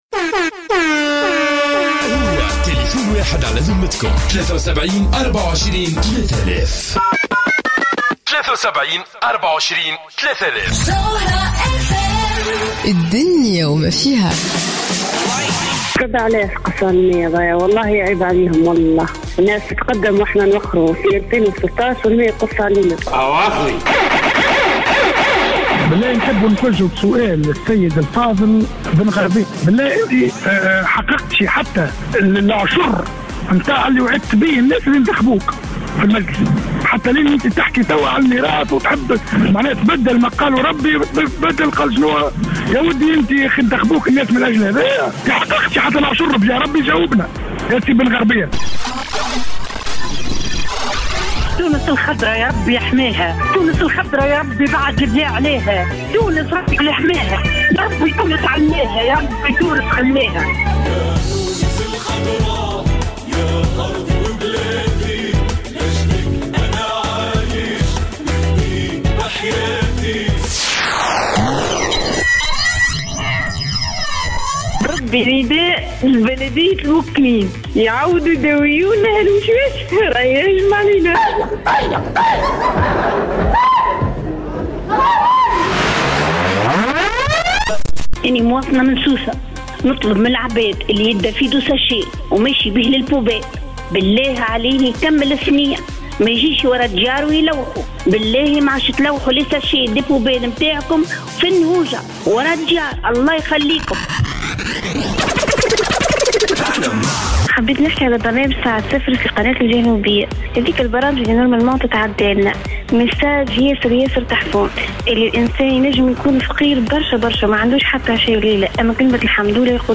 مستمعة : نداء لبلدية المكنين يداويو الوشواشة راهي هاجمة علينا